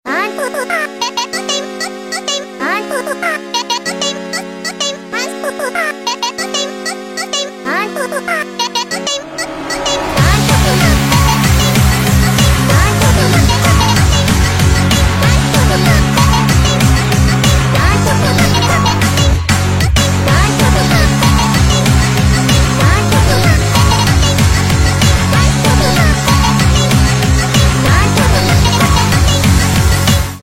энергичные